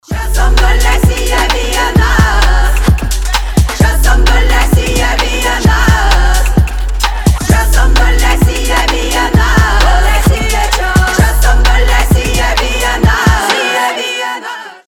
• Качество: 320, Stereo
атмосферные
Народные
фолк
Интересное соединение рэпа и фолка